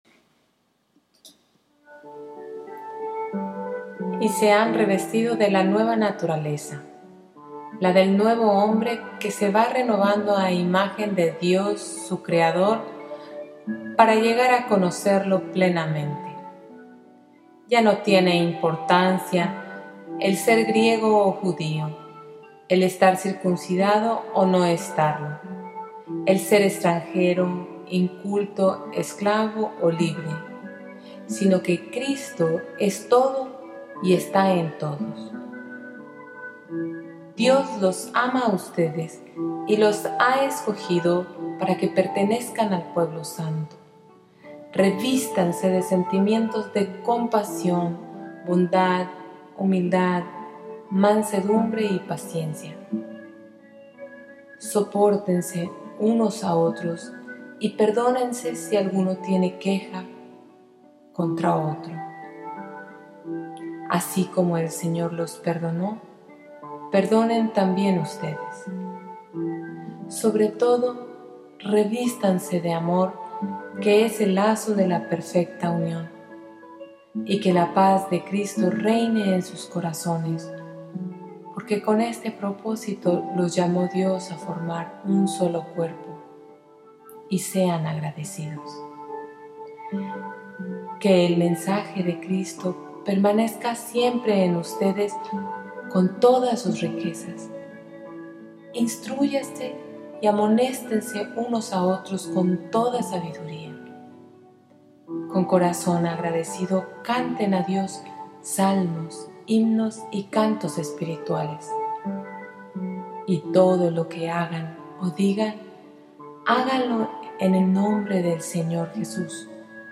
Lectura Biblia